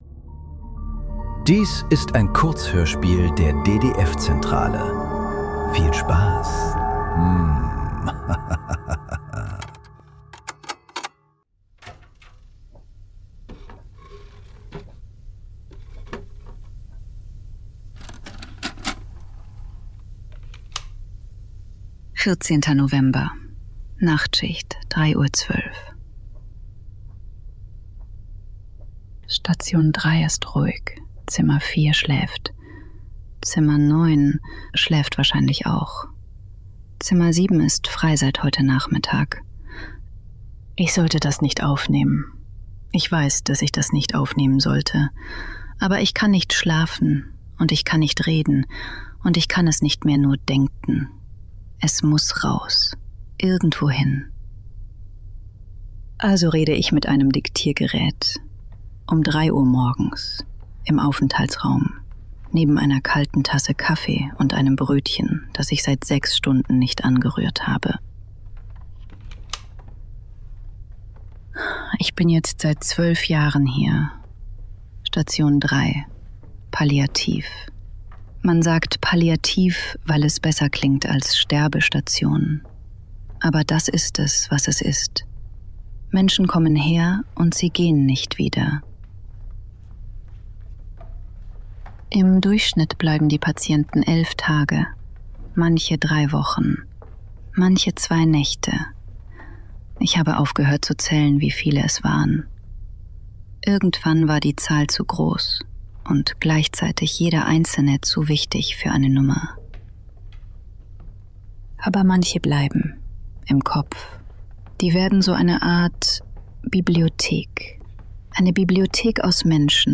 Kurzhörspiele. Leise.
Nachtschicht im Hospiz. Eine Krankenschwester spricht auf ein Diktiergerät. Über die Menschen, die sie hat sterben sehen.